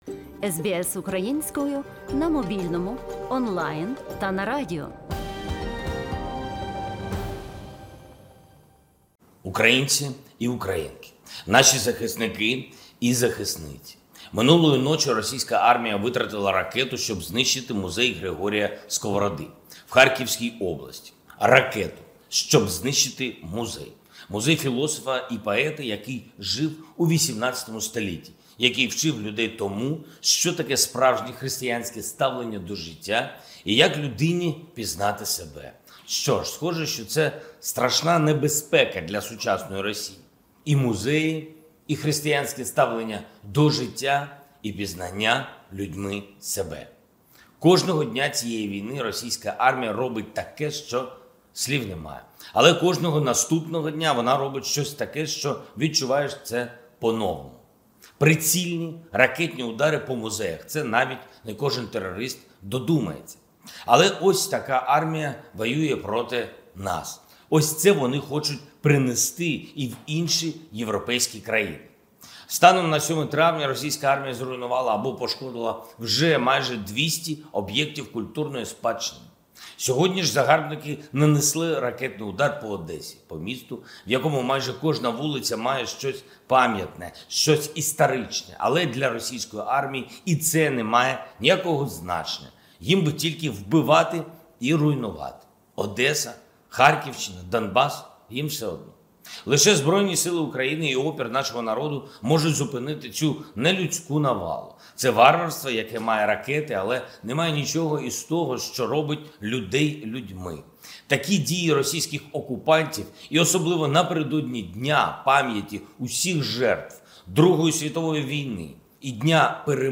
Звернення Президента України Володимира Зеленського